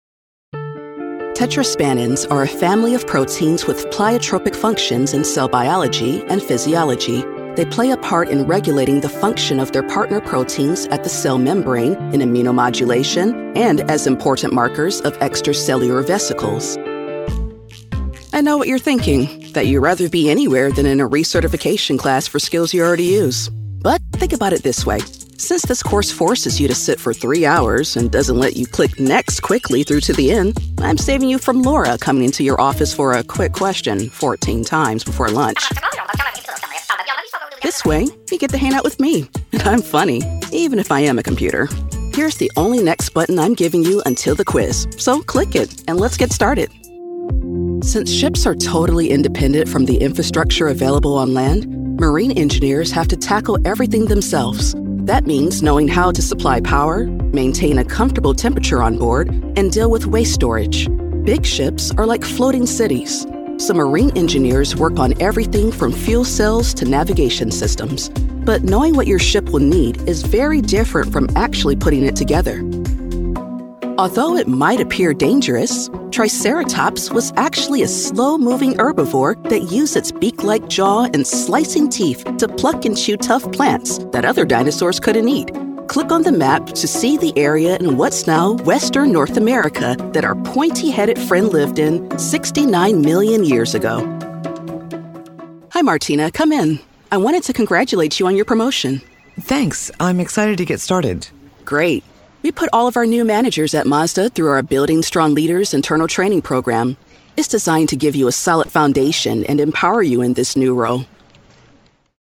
Female
Character, Confident, Corporate, Engaging, Friendly, Natural, Smooth, Warm, Versatile
Coaches and clients have described my voice as warm, full of smile, and versatile.
ANIMATION_Demo.mp3